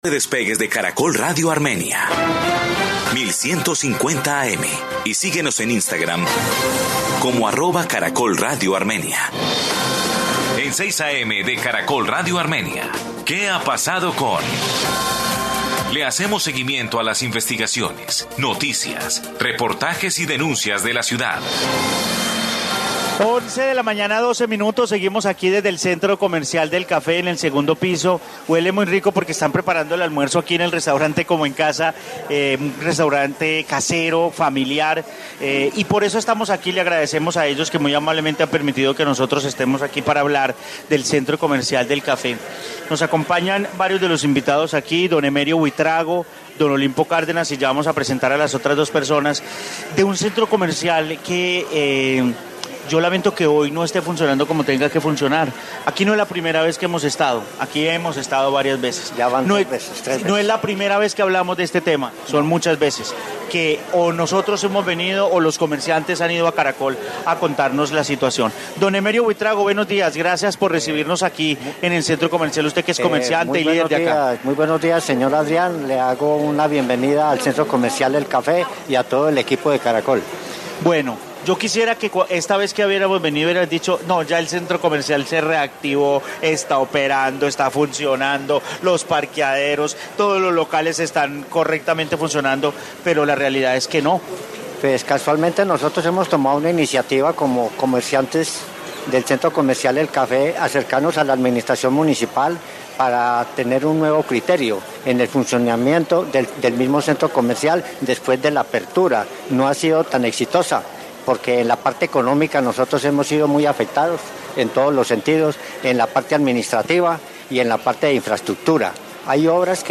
Informe Centro Comercial del Café de Armenia